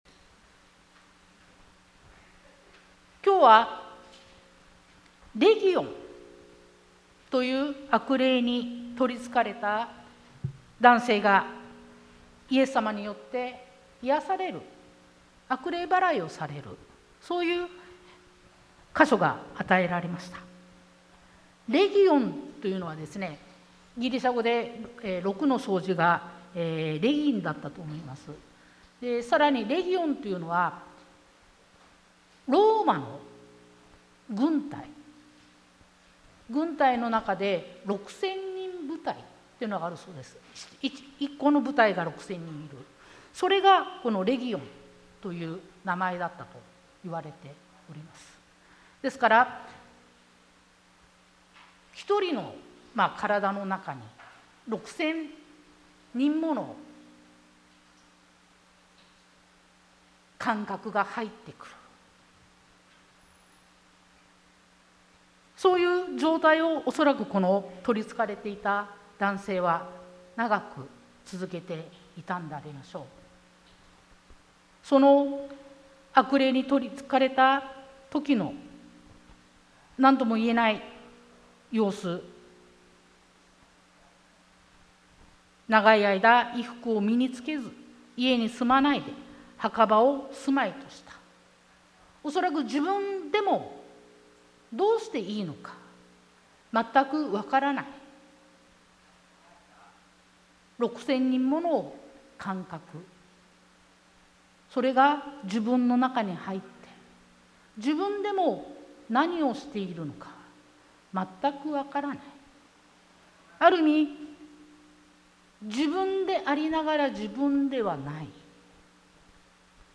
sermon-2023-03-05